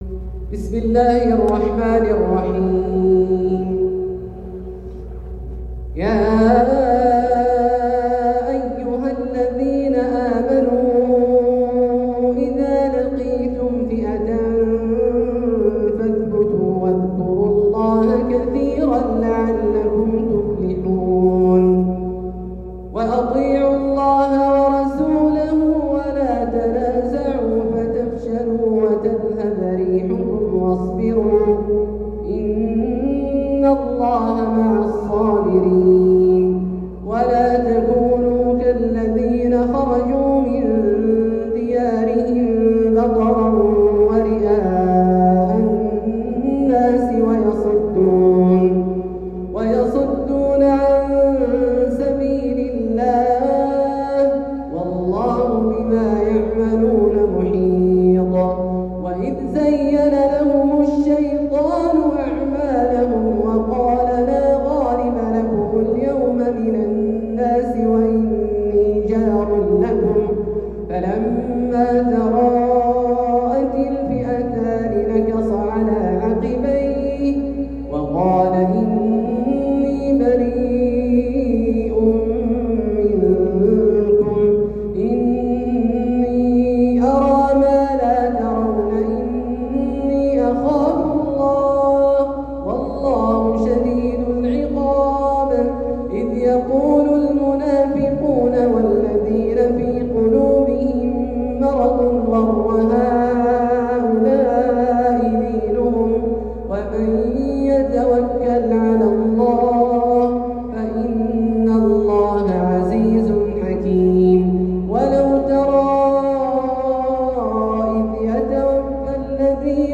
تلاوة من سورة الأنفال للشيخ عبدالله الجهني في جامع الملك عبدالله بمدينة الملك فيصل العسكرية > زيارة فضيلة الشيخ أ.د. عبدالله الجهني للمنطقة الجنوبية | محرم 1447هـ > المزيد - تلاوات عبدالله الجهني